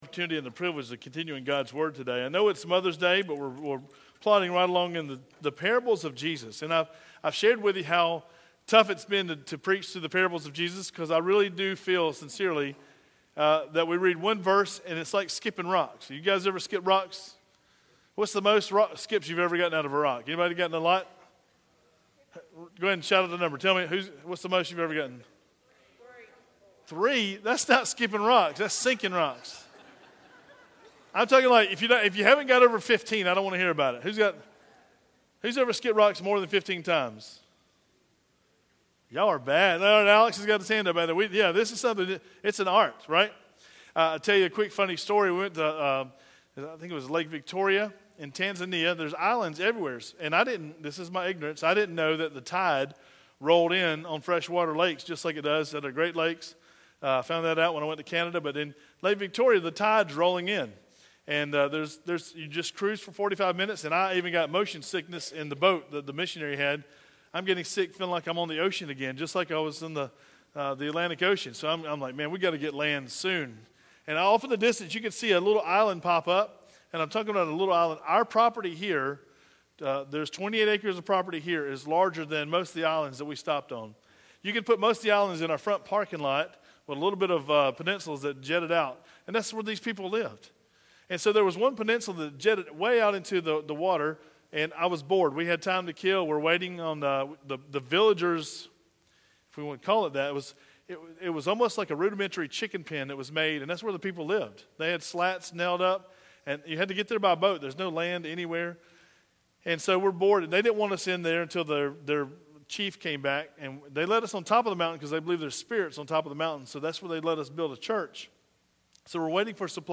Sermon Direct Link Matthew 13 | Numbers 23 | Genesis 12 | John 1 | Deuteronomy 7